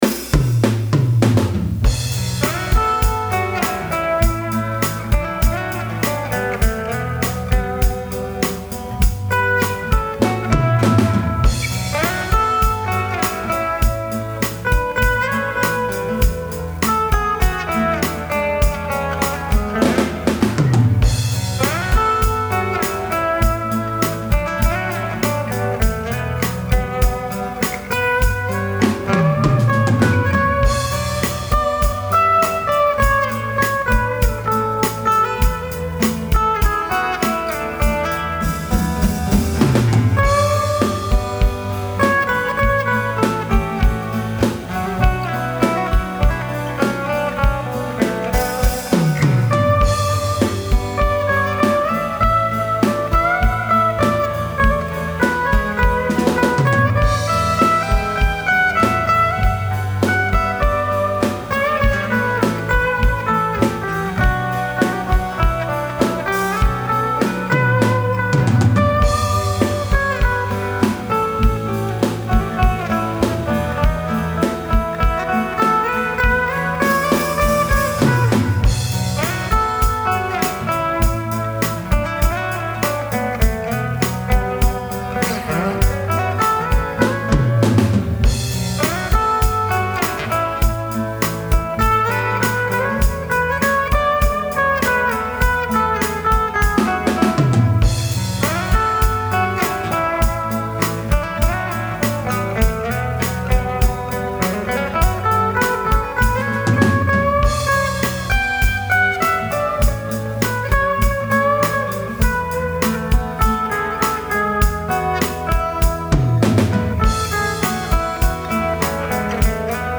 New Gear: BeatBuddy ~ The First Pedal Drum Machine
You start out with a tap to get an intro fill.
Here’s something I quickly put together once I got the hang of it. Excuse the little mistakes I made. I did both guitar tracks in single takes.